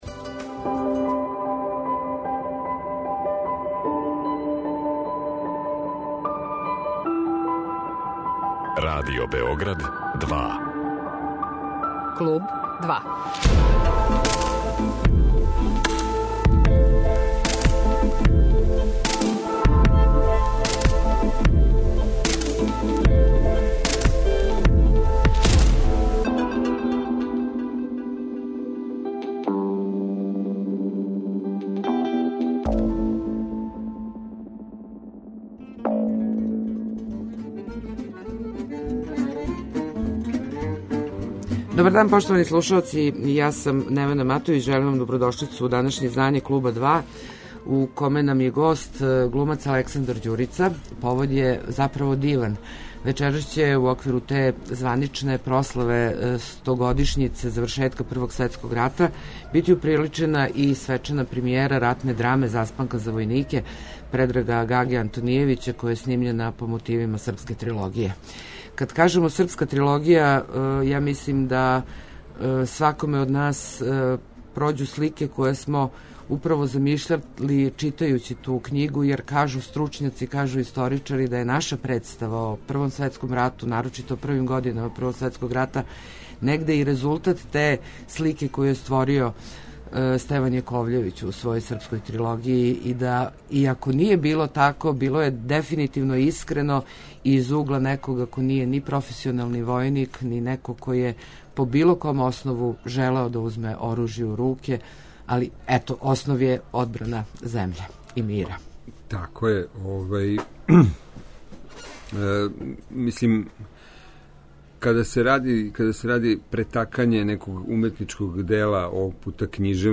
Гост је глумац Александар Ђурица